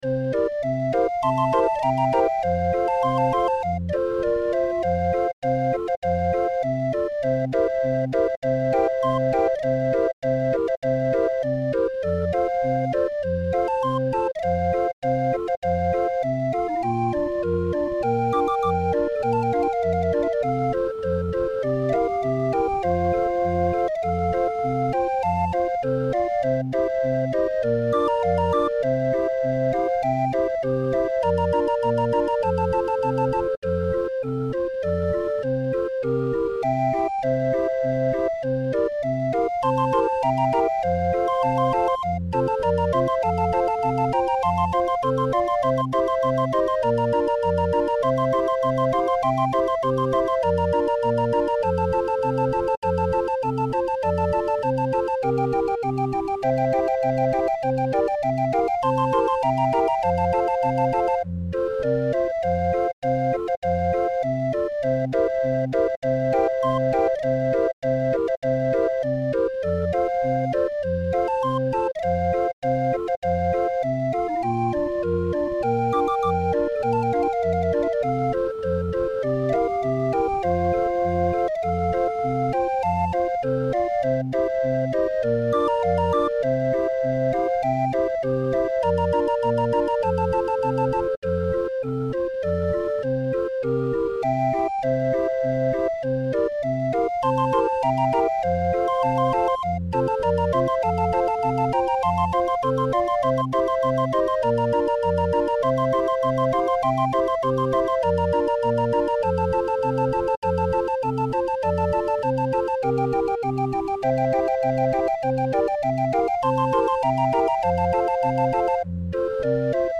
20-er draaiorgel midi-file